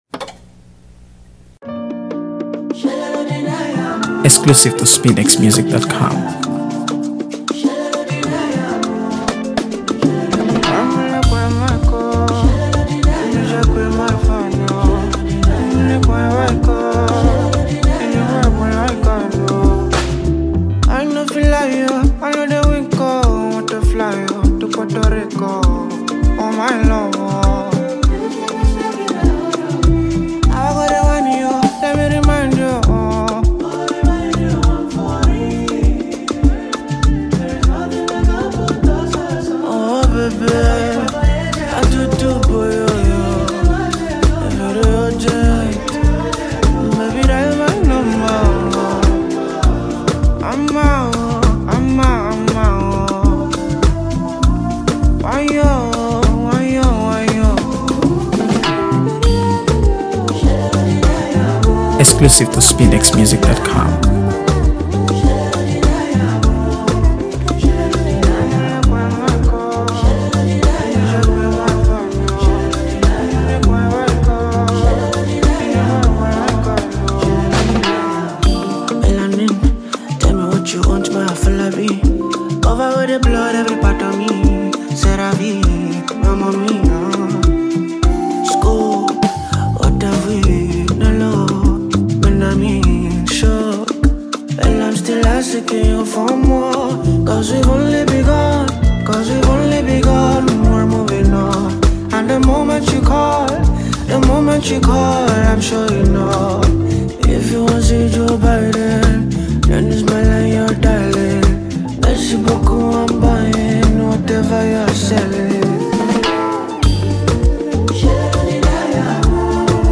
AfroBeats | AfroBeats songs
smooth, irresistible vocals
creating a track that’s both sultry and emotionally charged.